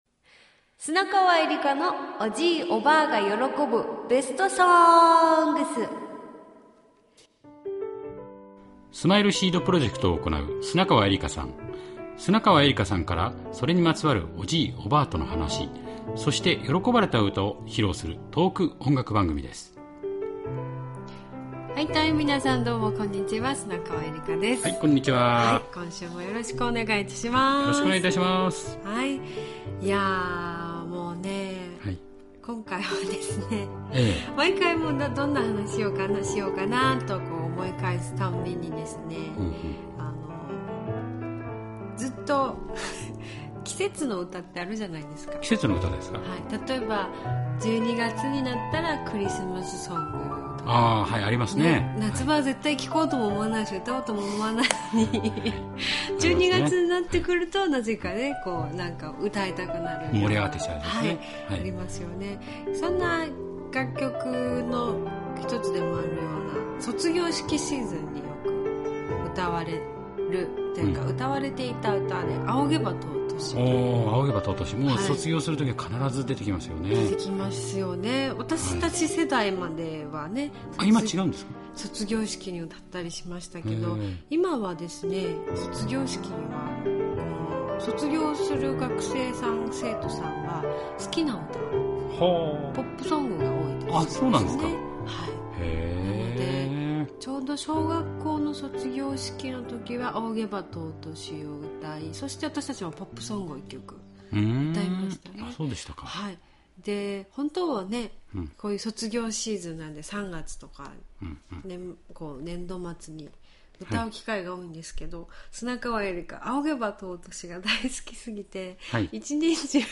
仰げば尊しが好きすぎて一年中歌っていた砂川恵理歌さんは、当然のようにスマイルシードプロジェクトでも歌うことになったといいます。